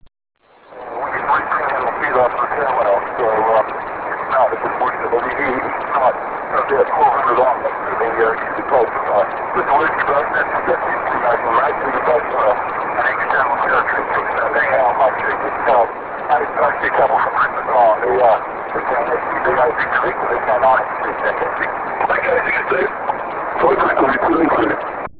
He sent me a large number of real audio files that include 50MHz QSOs and some beacons audio.